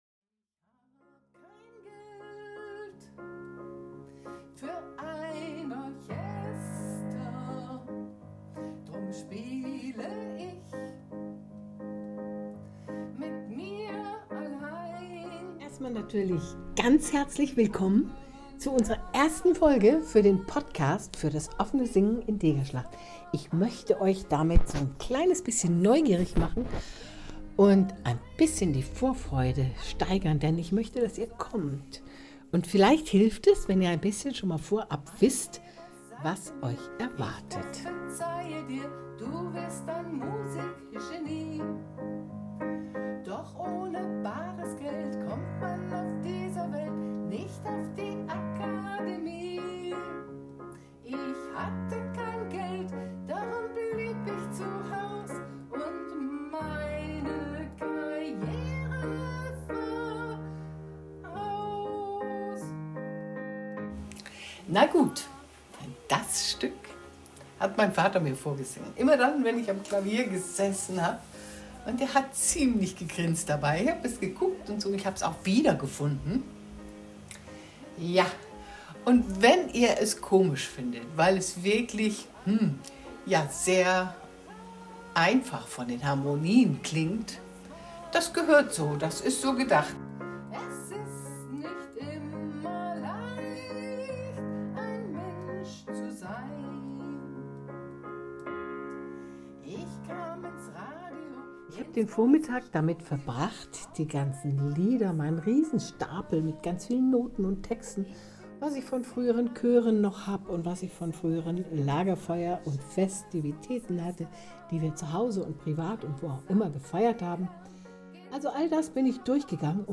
In dieser Podcast-Folge nehme ich euch mit in meinen Probenraum. Da steht ein Klavier und eine alte Gitarre hab ich in einer Ecke auch noch gefunden. Ich nehme euch mit in mein Chaos aus Liederheften und losen Zetteln und wie ich versuche, irgendwas aus meiner Erinnerung hervorzukramen, um ein Repertoire für das Offene Singen zusammenzustellen.